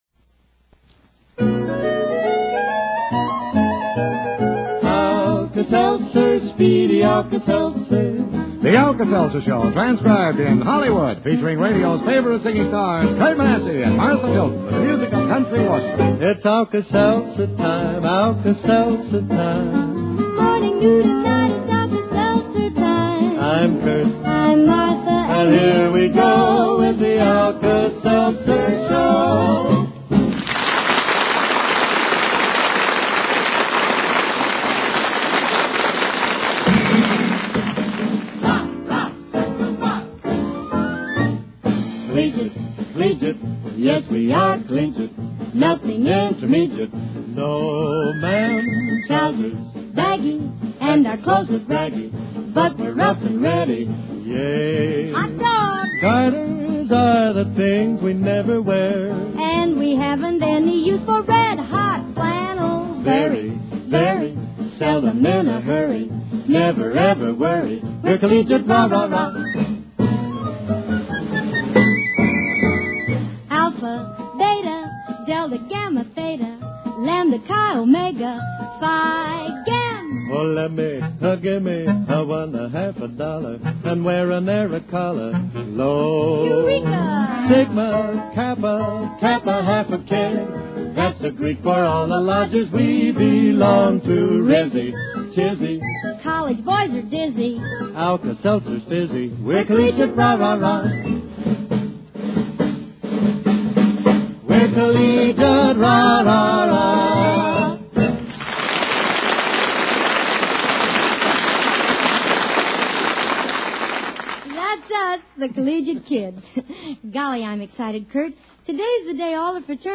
The system cue is added live.